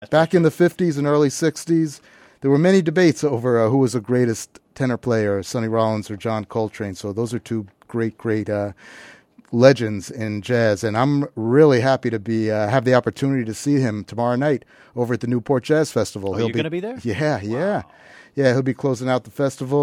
全部で1時間強と尺があるので、つなぎの語りから実際にそのトラックを聴かせる所までの、